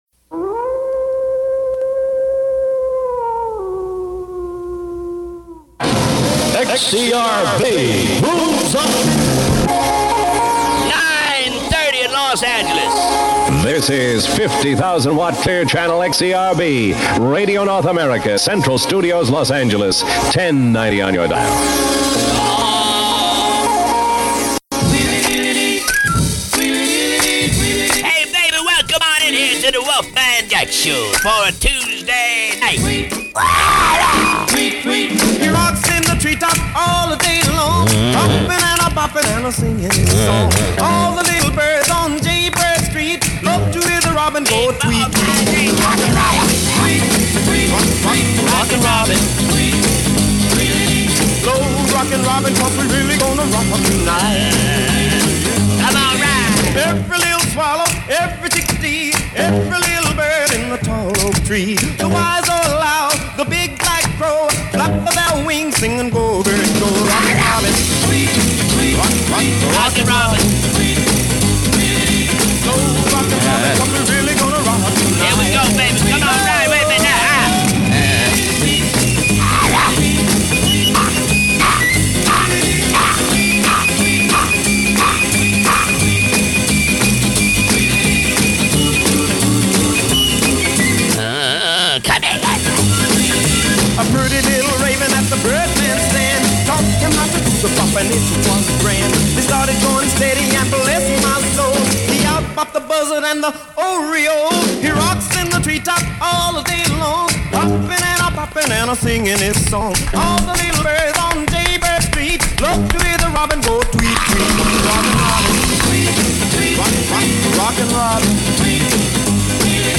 Before anyone knew his face, they knew that unmistakable growl — raw, rhythmic, mischievous, and full of promise.